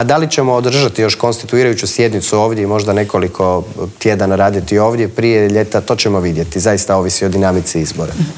ZAGREB - Dan nakon raspuštanja 10. saziva Sabora i uoči odluke predsjednika Zorana Milanovića da će se parlamentarni izbori održati u srijedu 17. travnja, u Intervjuu tjedna Media servisa gostovao je predsjednik Sabora Gordan Jandroković.